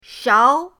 shao2.mp3